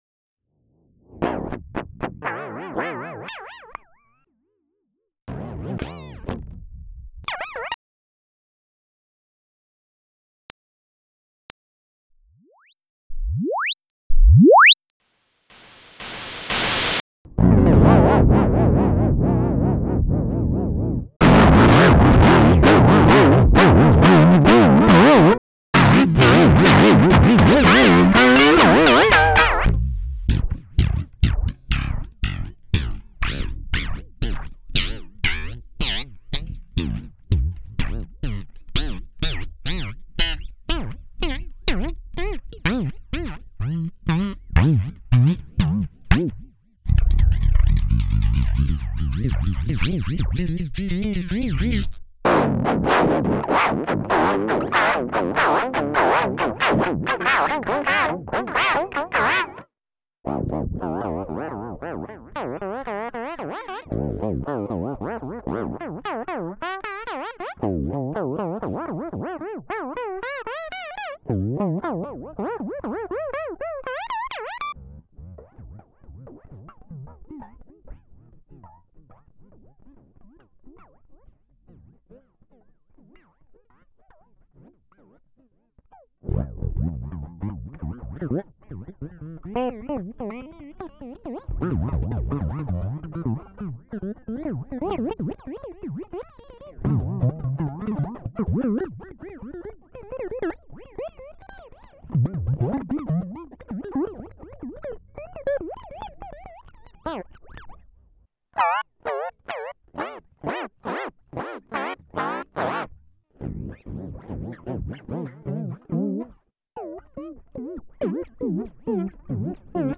Creating models of your gear using the sweep signal is the standard method. This creates the most versatile tone model that will work with any input signal.
T3K-sweep-v3.wav